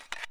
Explode.wav